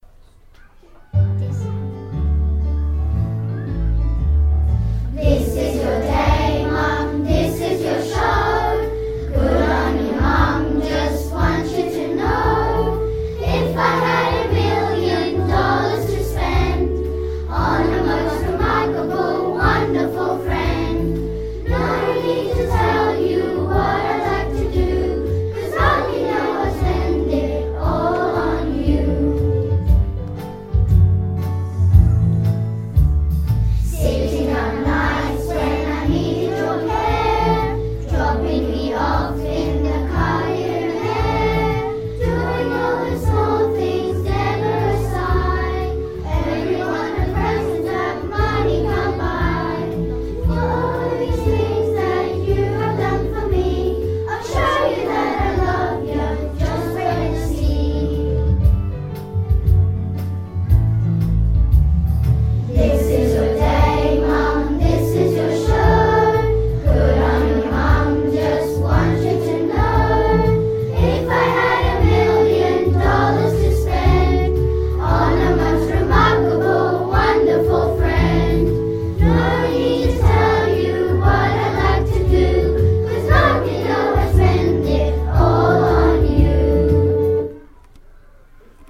mothers-day-choir-song.mp3